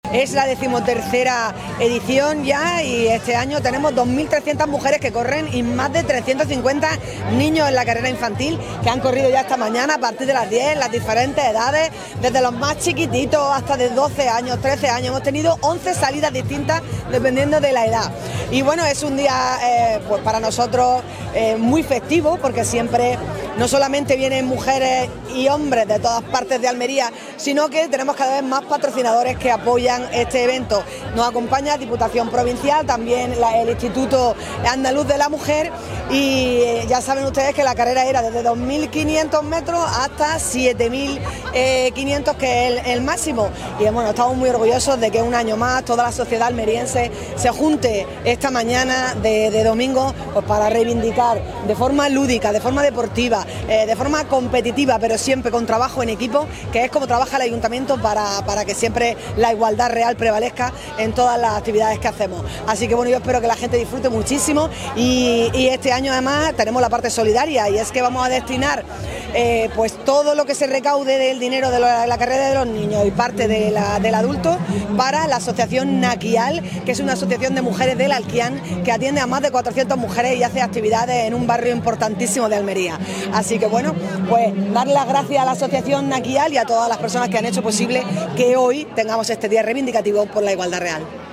ALCALDESA-8M-XIII-CARRERA-DE-LA-MUJER.mp3